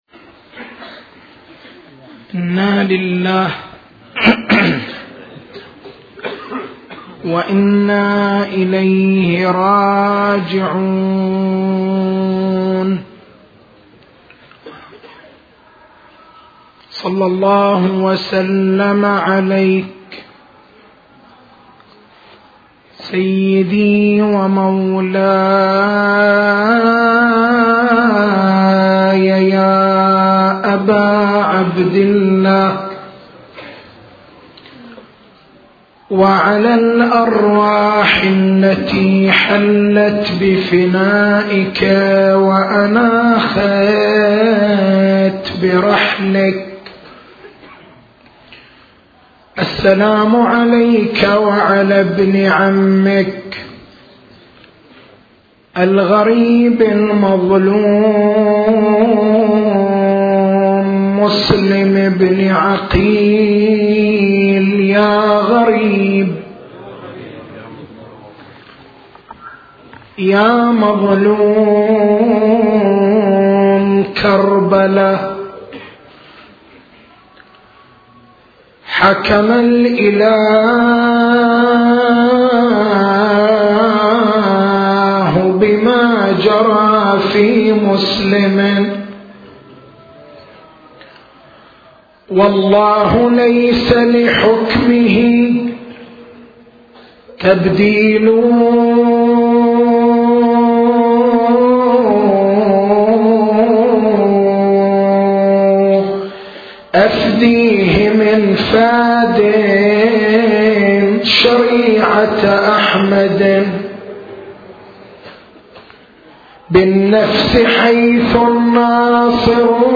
تاريخ المحاضرة: 04/01/1428 نقاط البحث: رؤية الشارع المقدّس لثقافة احترام التخصّص عرض السيرة العقلائية إمضاء الشارع لهذه السيرة حاجة الثقافة الدينية للتخصّص تدريجية المعارف الدينية توقّف المعارف الدينية على تنقيح بعض المقدّمات العلمية تعارض النصوص الدينية نقد الشبهات المثارة على حاجة الدين للتخصّص لا تقليد في العقائد الدين للجميع التسجيل الصوتي: تحميل التسجيل الصوتي: شبكة …